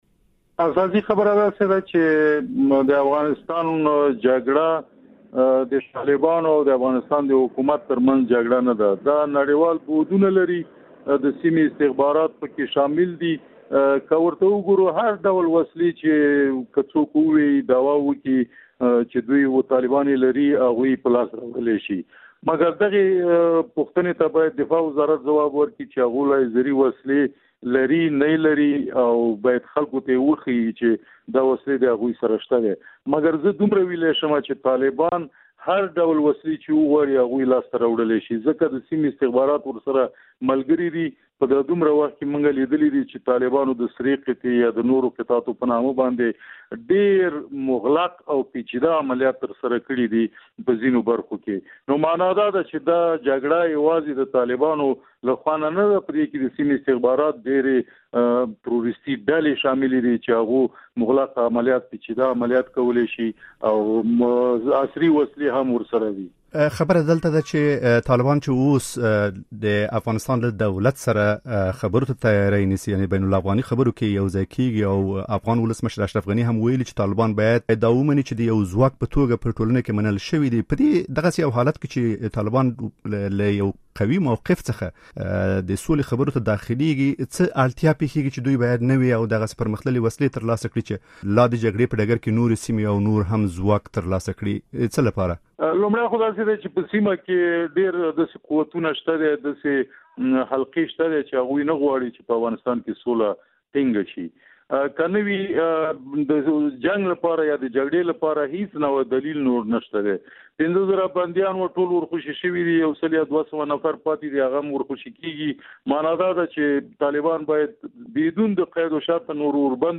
مرکه
له دولت وزیري سره مرکه